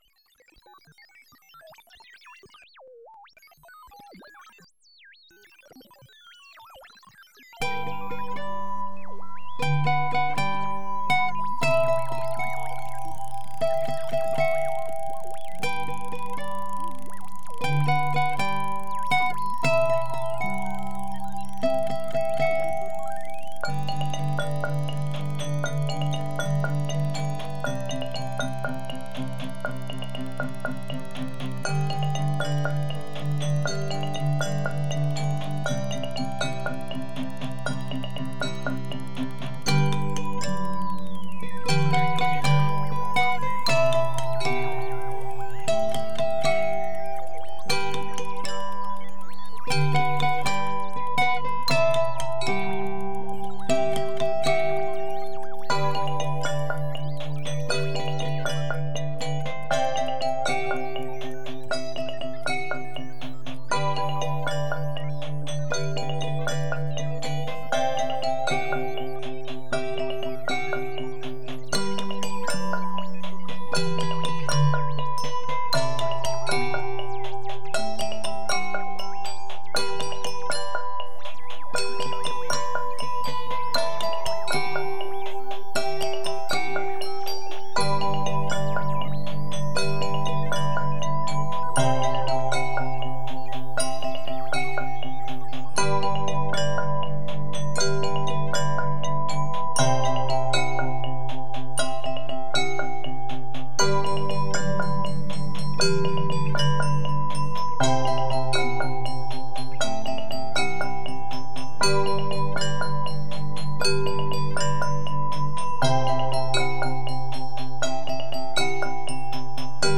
compose et joue de centaines de jouets musicaux.